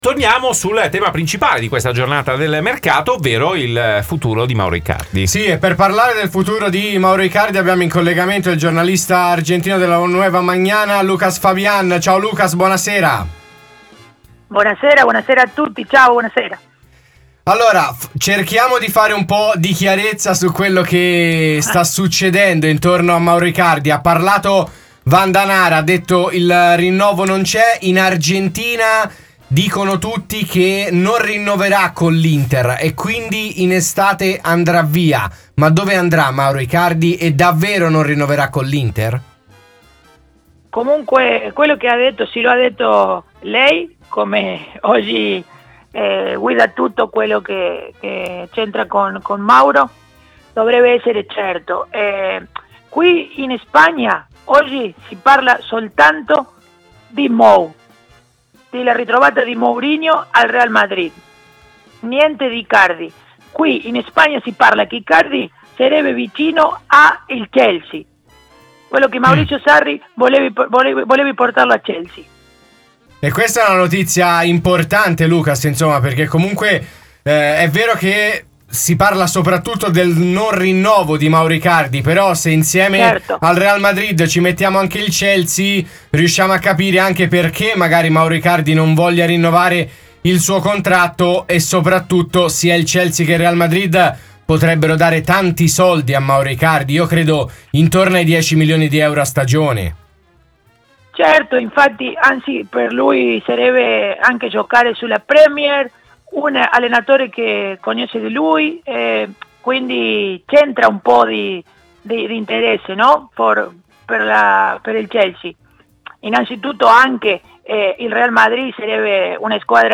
si è espresso così sul caso Icardi e sul possibile ritorno di Mourinho al Real Madrid in diretta nel 'Live Show' di RMC Sport.
giornalista argentino